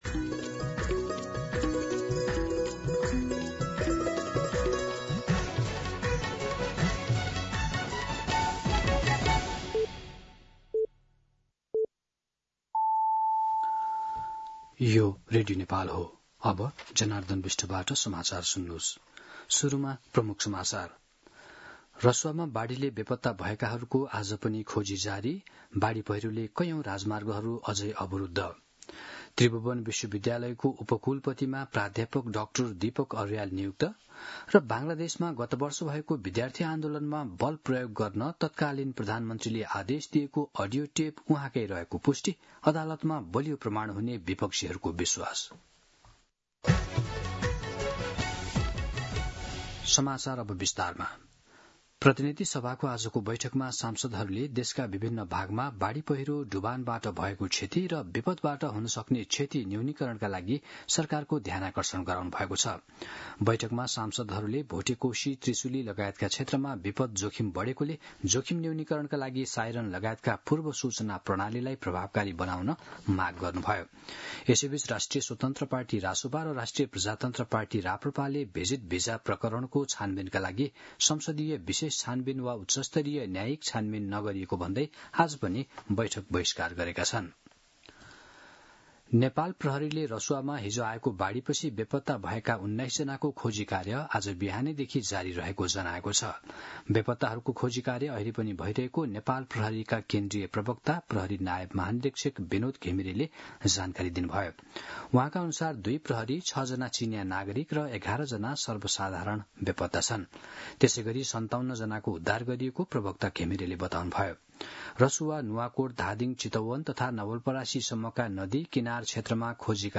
दिउँसो ३ बजेको नेपाली समाचार : २५ असार , २०८२